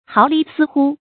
毫厘丝忽 háo lí sī hū 成语解释 古代“分”以下四个微小长度单位的并称。